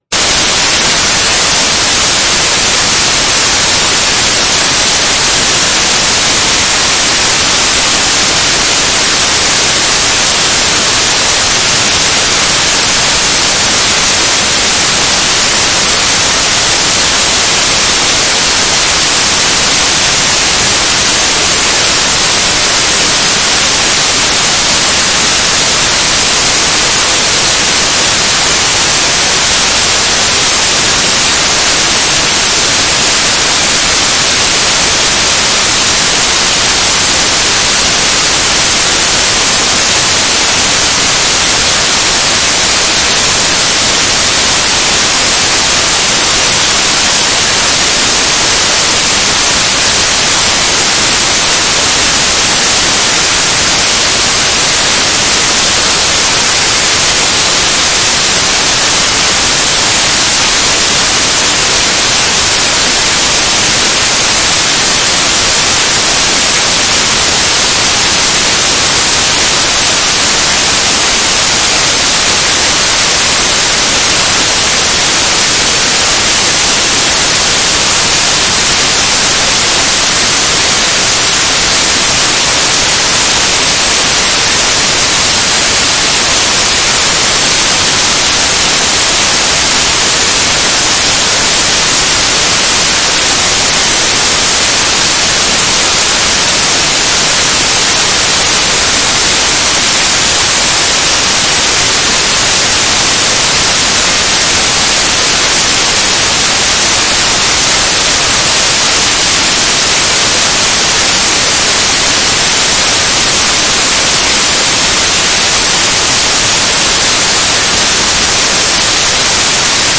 Judges 2:6 Service Type: Sunday Evening %todo_render% « What Do You Do When Your Brook Dries Up?